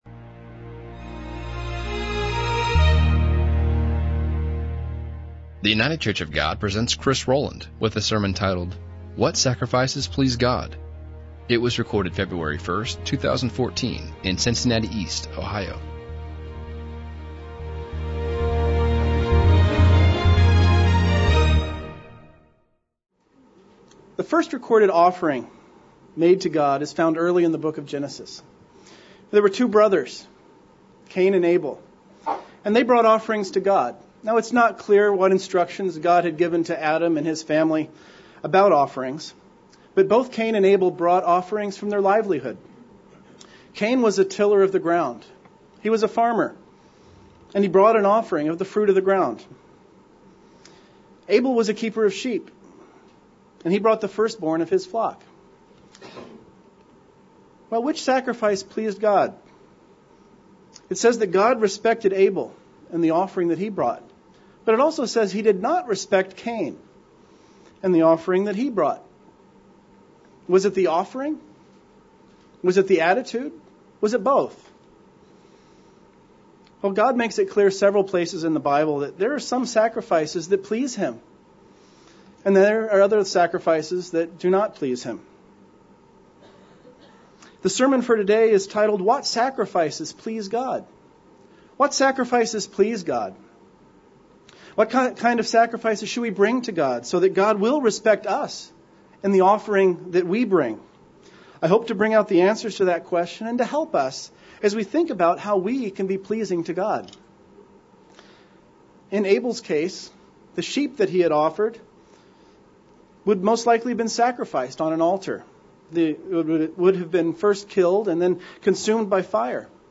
What sacrifices please God, what kind of sacrifices shall we bring to God so that God will respect us and the offering that we bring? This sermon focuses on seven points about the sacrifices that please God and what it means to be a living sacrifice.